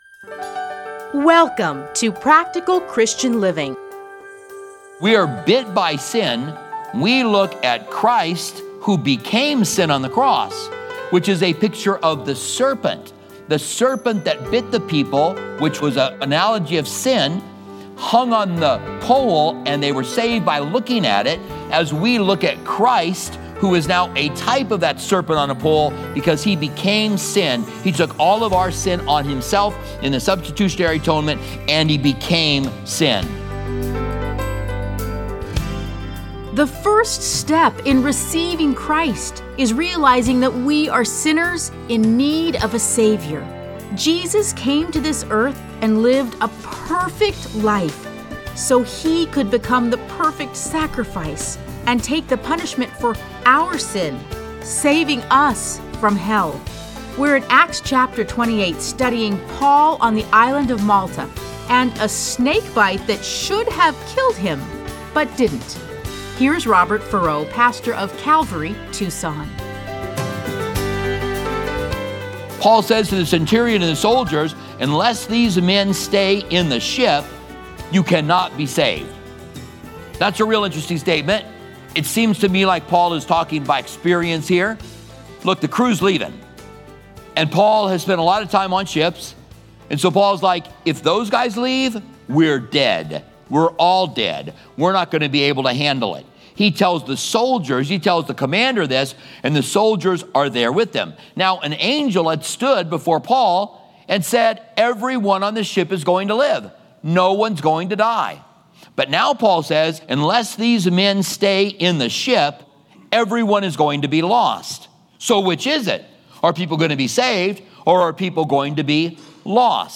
Listen to a teaching from Acts 27:27-28.